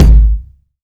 Kick (123).wav